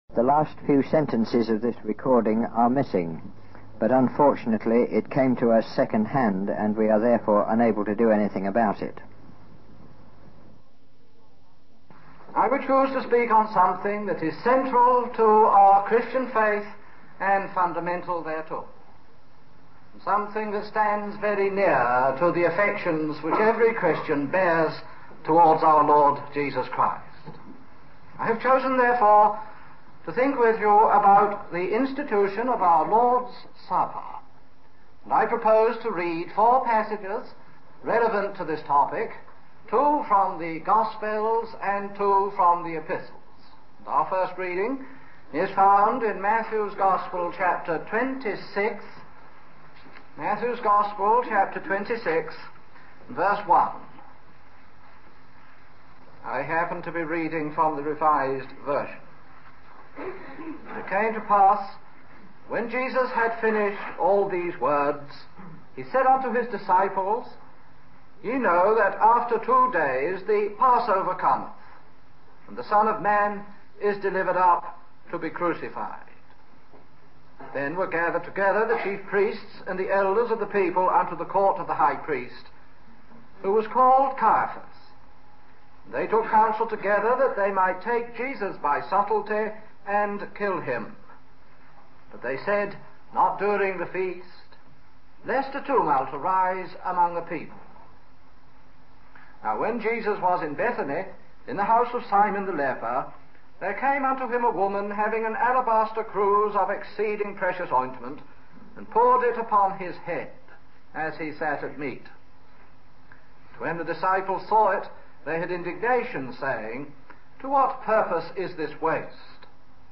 In this sermon, the speaker focuses on the institution of the Lord's Supper, which is central to the Christian faith. He reads four passages from the Bible, two from the Gospels and two from the Epistles, to support his discussion. The speaker emphasizes the importance of the Lord's Supper and compares it to a last chance to show love, using the example of giving a gift to a loved one who is about to die.